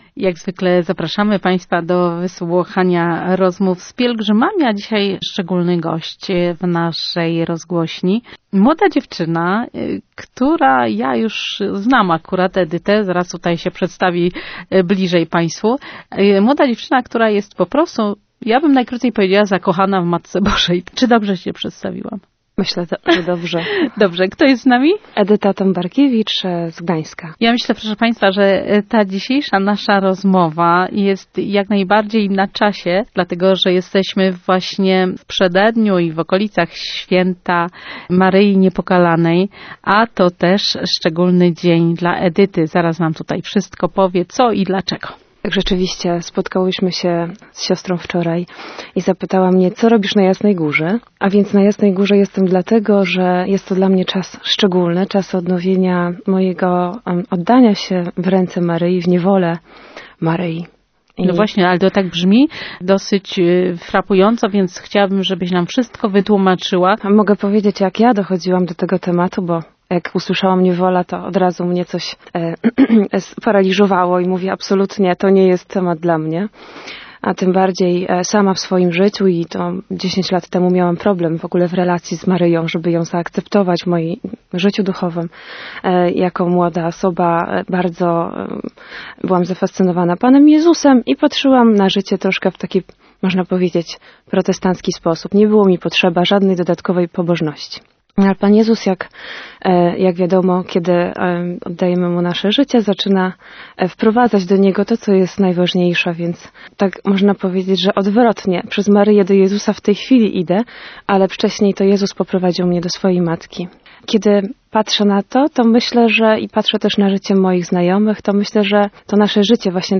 Świadectwo złożone podczas Wigilii Święta Bożego Miłosierdzia 2.04.2016
Świadectwo zawierzenia Maryi